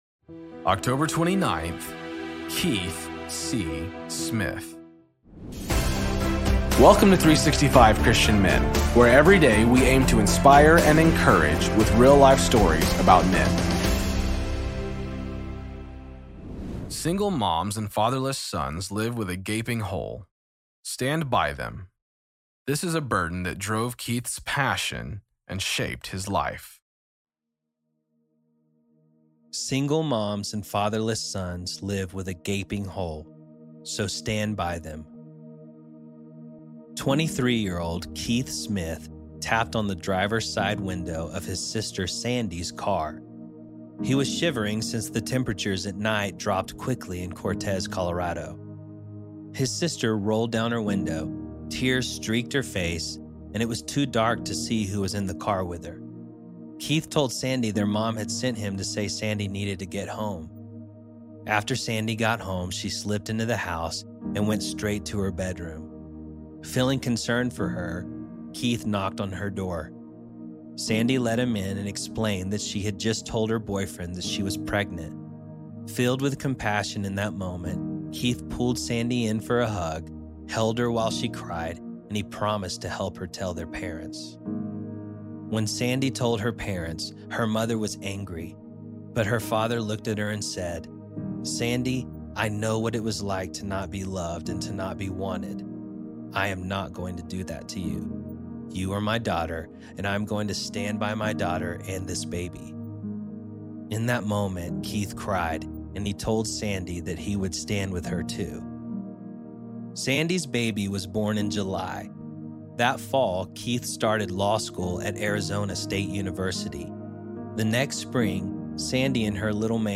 Story read by:
Introduction read by: